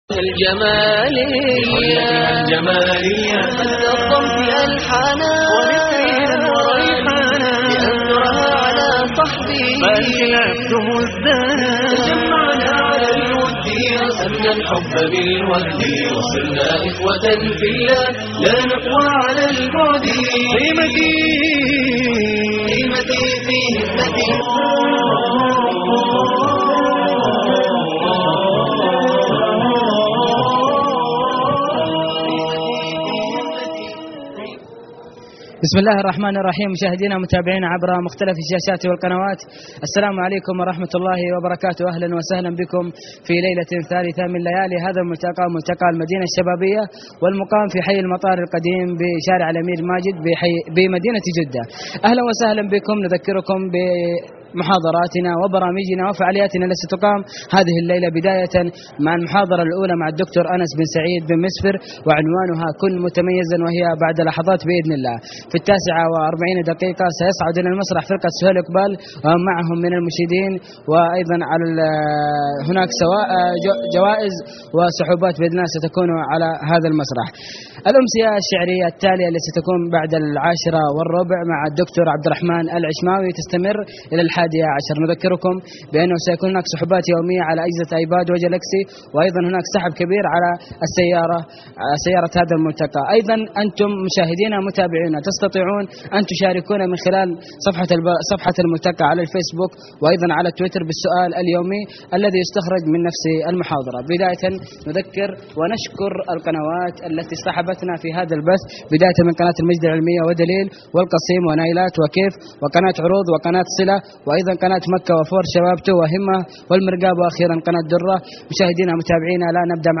كن متميزًا (26/8/2012) ملتقى المدينة الشبابية